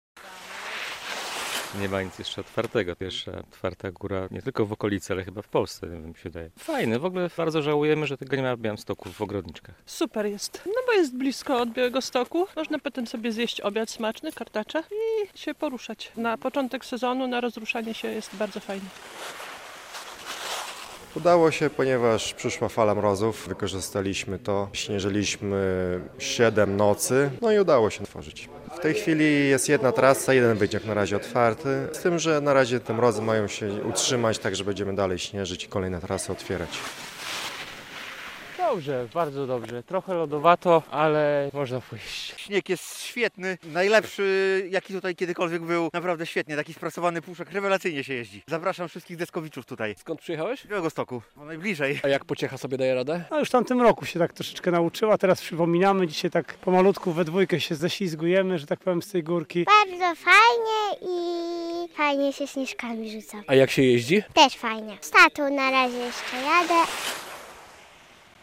Narciarze mogą już korzystać ze stoku w Rybnie koło Łomży - relacja
Narciarze i snowboardziści, którzy przyjechali zaraz po otwarciu stoku, chwalili jego przygotowanie.